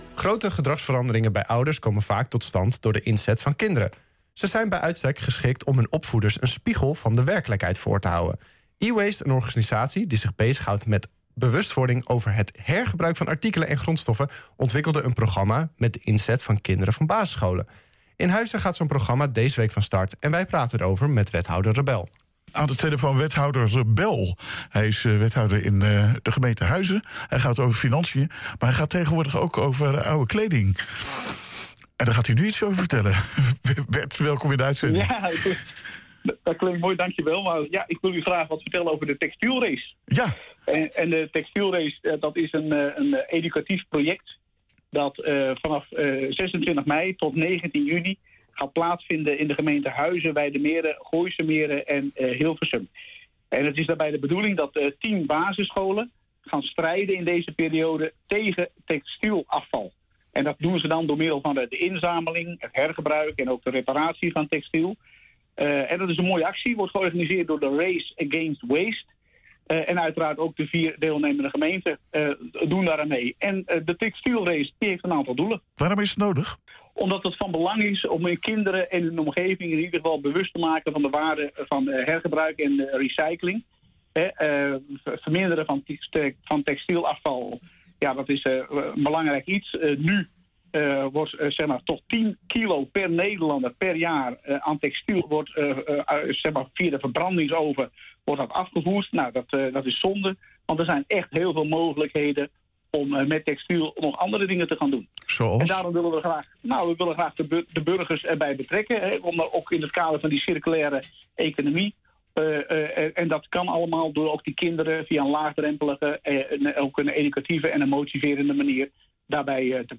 In Huizen gaat zo'n programma deze week van start en wij praten er over met wethouder Rebel.